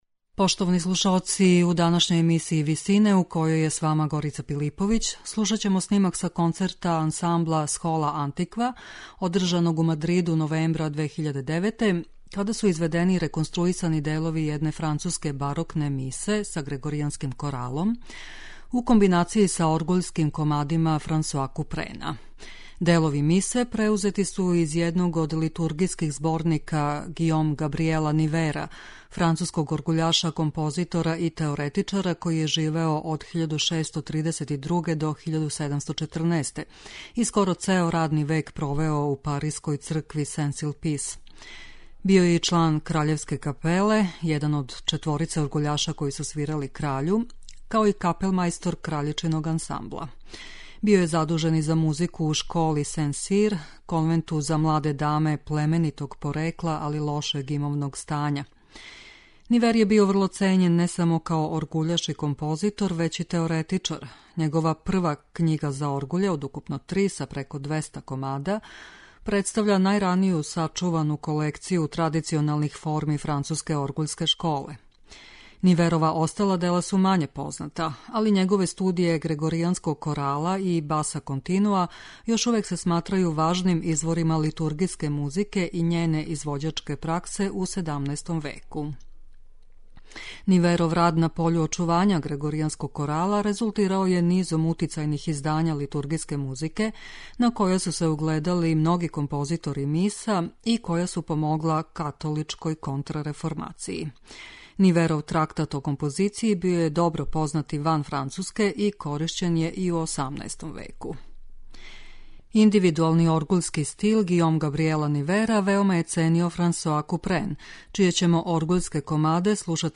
У емисији Висине можете слушати снимак на којем су чланови вокалног ансамбла Схола антиква
оргуљаш
напеве грегоријанског корала са оргуљским комадима
у ВИСИНАМА представљамо медитативне и духовне композиције аутора свих конфесија и епоха.